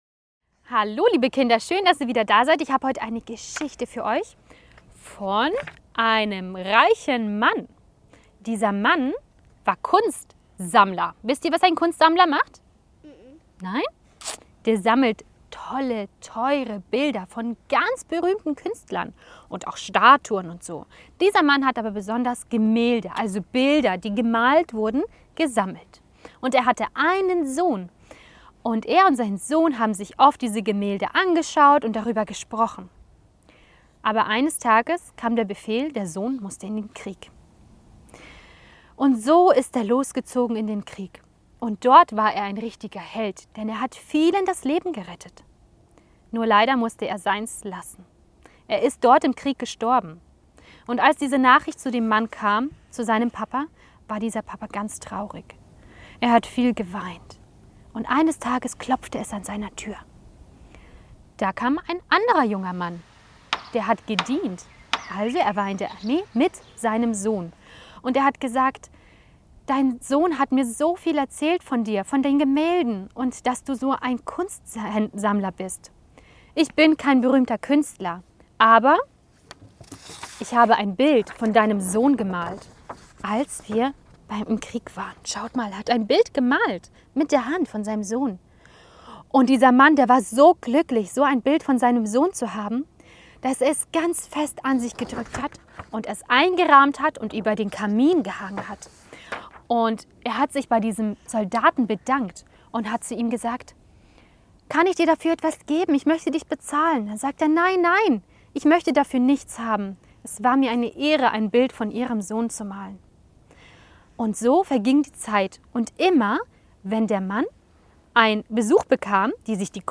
Wahre Kurzgeschichten für Kinder